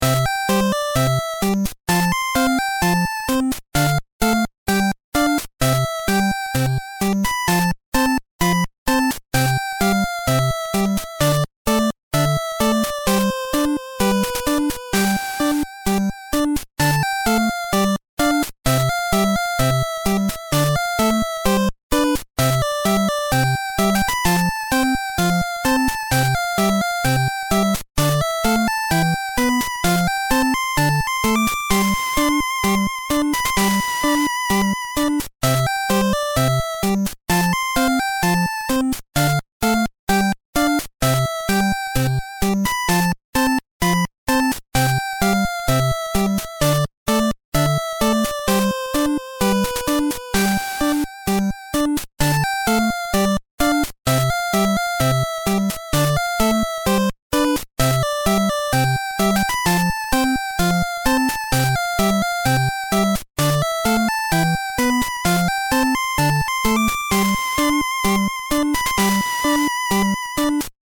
8-bit chiptune song called "Bird Park."